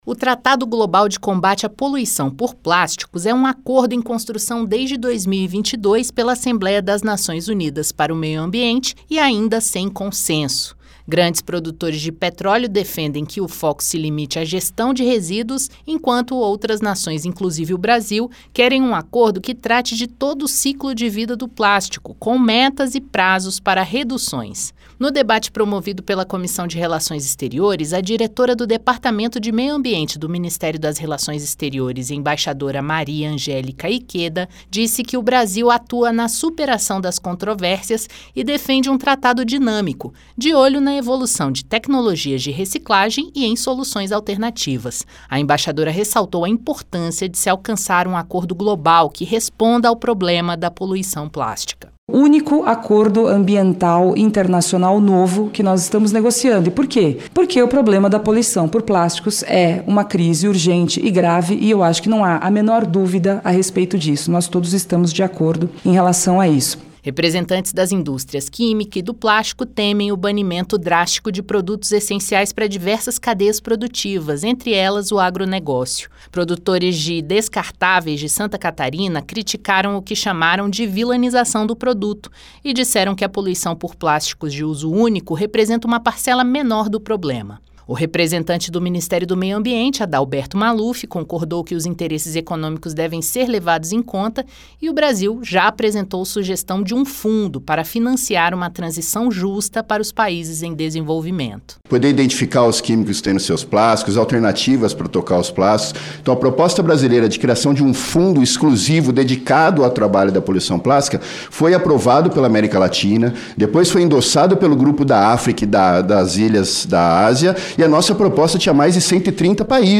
No debate sobre o Tratado Global de Combate à Poluição por Plásticos, na Comissão de Relações Exteriores (CRE), na quinta-feira (24), foram ouvidos representantes do Itamaraty, do Ministério do Meio Ambiente, dos catadores de recicláveis, das indústrias química e de plástico; além da academia e de organizações ambientais e de saúde coletiva. O papel do Brasil na construção do acordo foi destacado. Mas desafios internos como a transição justa para quem hoje depende do plástico ainda persistem.